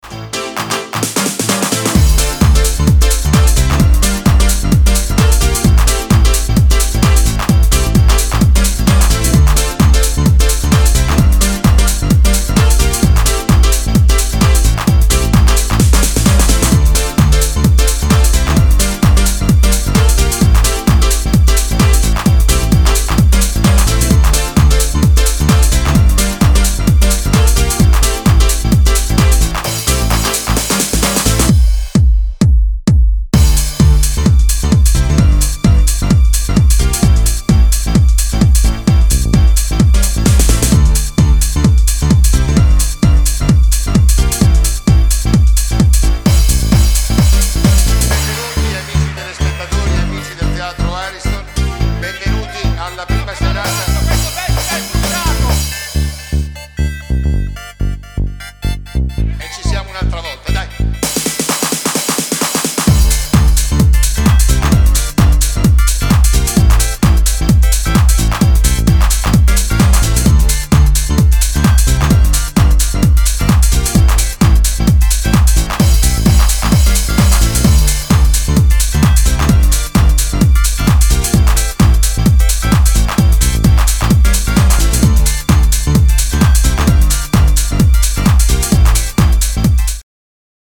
いずれも、90s前半のイタリアン・ハウスの情緒的な部分までもモダンなプロダクションできちんと汲み取った意欲作。